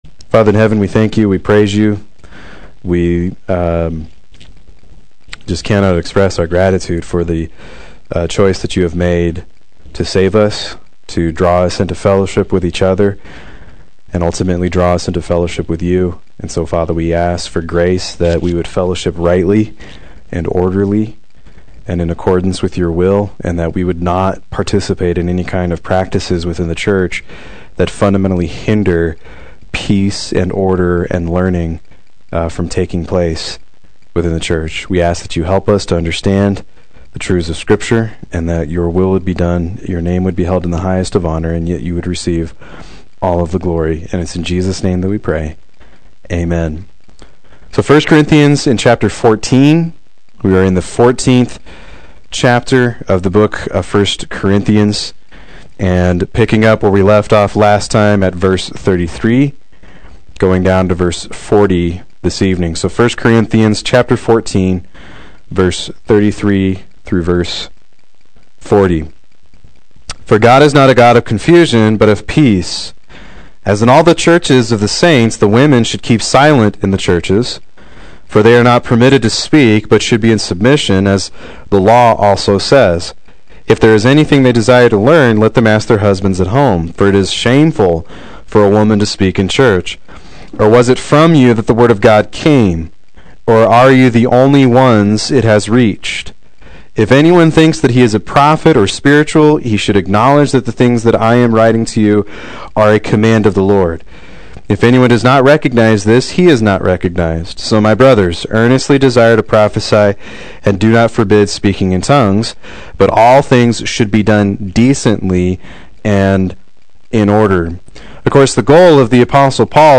Proclaim Youth Ministry - 10/14/16
Play Sermon Get HCF Teaching Automatically.